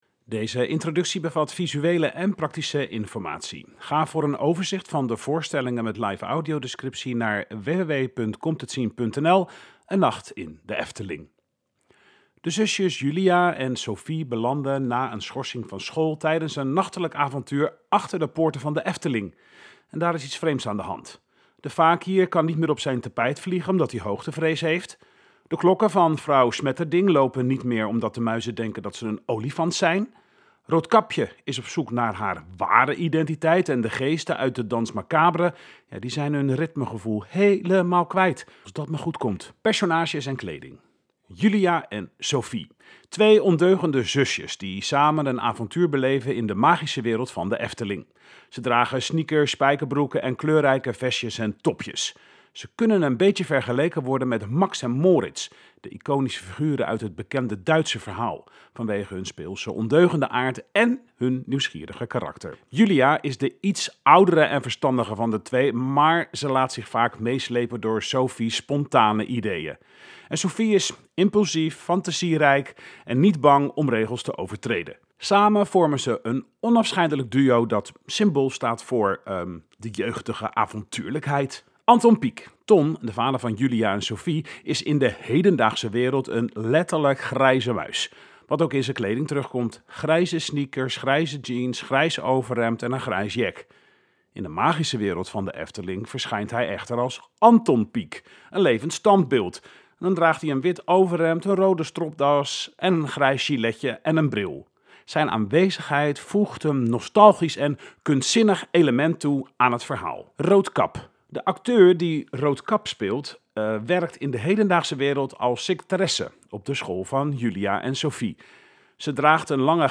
De introductie is hieronder beschikbaar als Word bestand, PDF, ingesproken audio en als website tekst